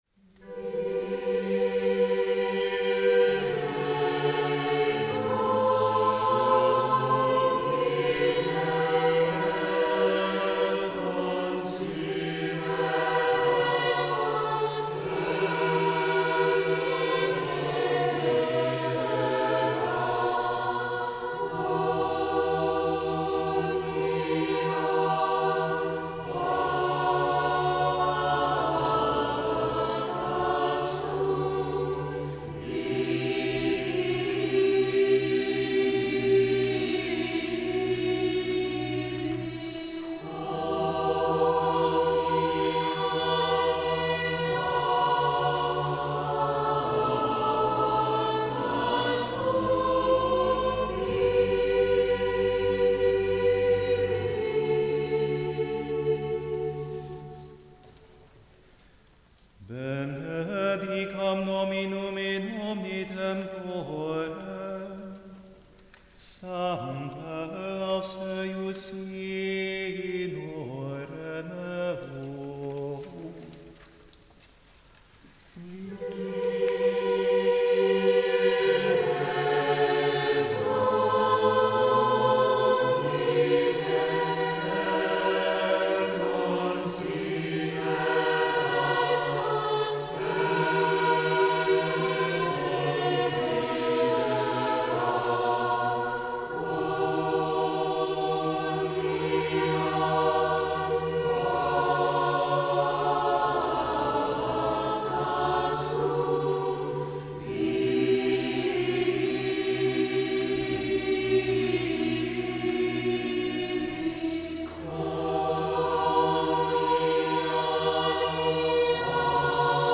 Vide Domine (SAB)
A penitential motet on the text “Look, O Lord, upon my affliction,” Vide Domine is rich with sighing motives and expressive cadences.
Live recording • Vide Domine